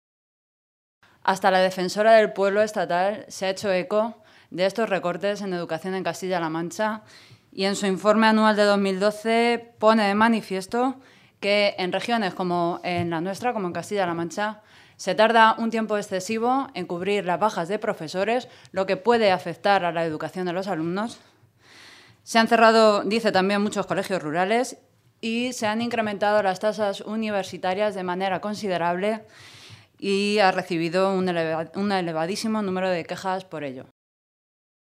Carmen Rodrigo, portavoz de Educación del Grupo Parlamentario Socialista
Cortes de audio de la rueda de prensa